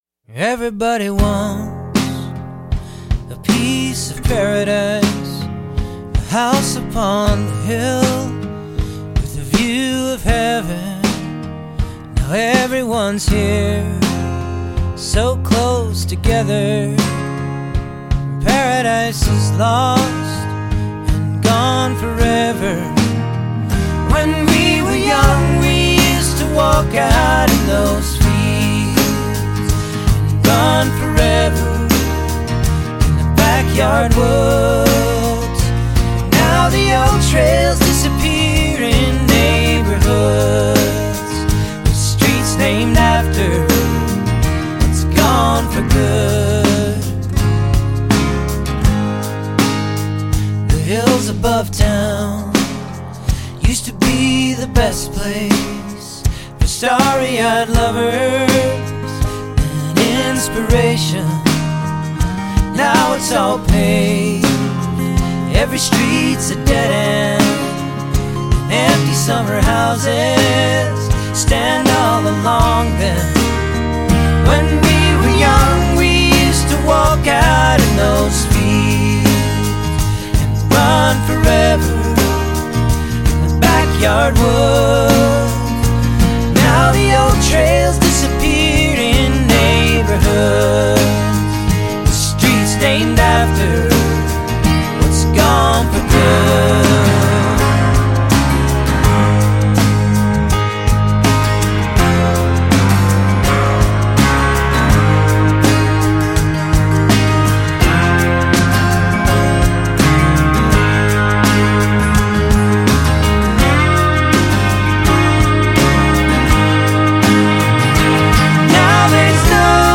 Love the harmony and the lyrics!